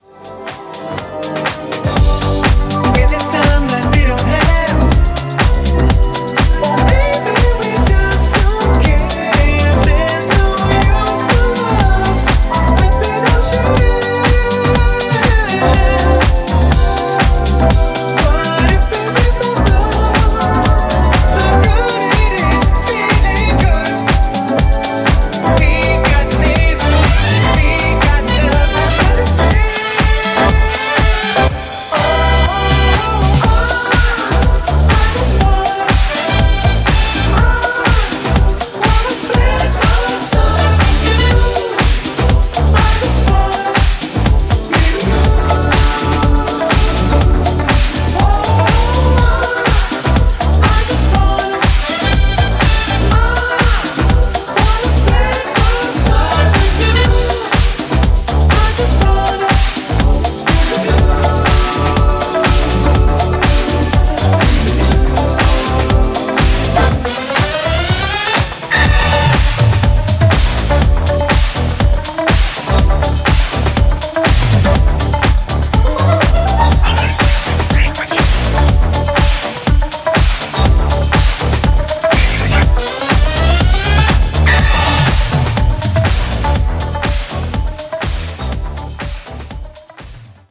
ジャンル(スタイル) DISCO / HOUSE